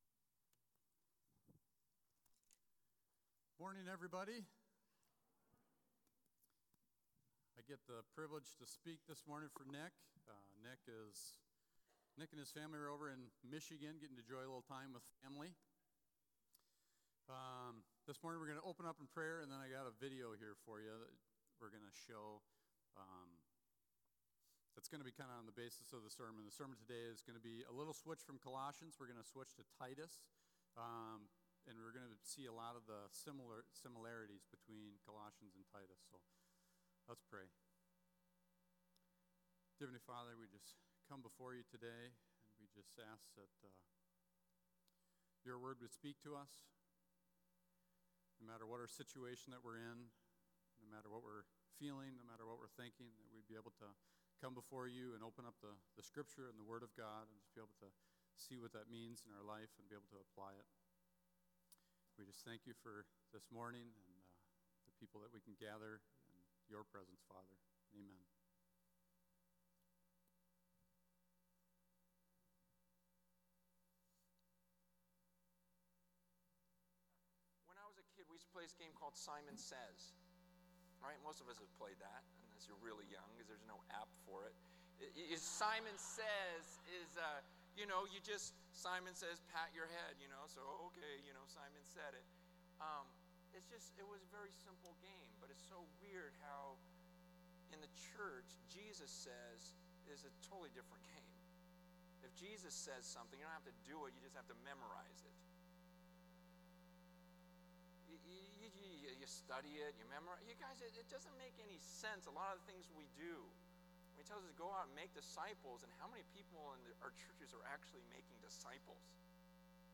Sermon-5-7-17.mp3